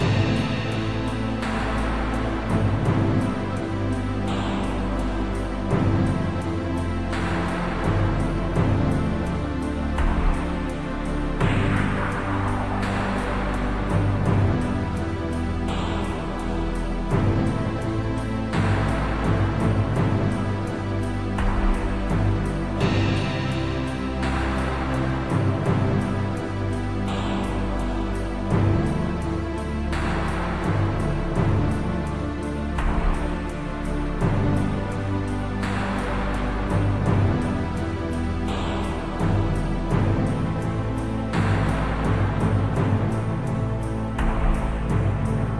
Small sentimental track suitable for a game-over screen.
• Music requires/does smooth looping